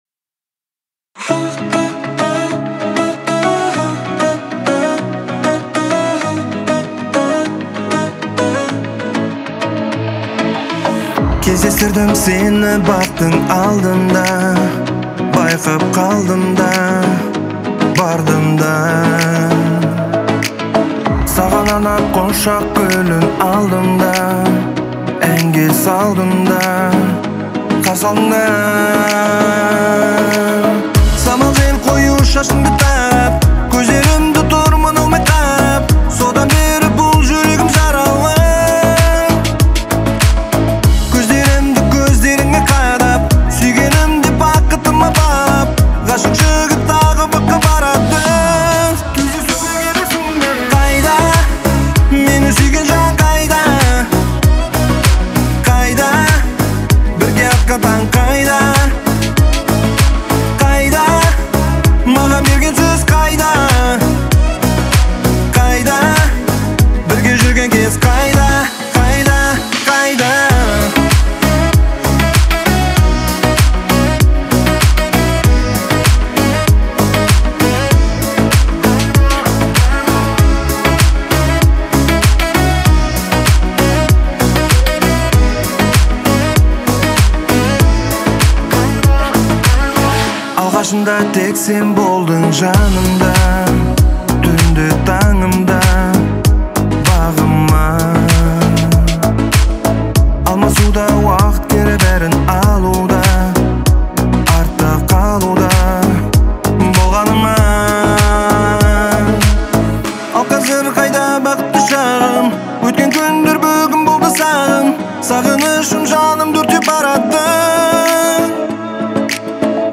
Трек размещён в разделе Казахская музыка.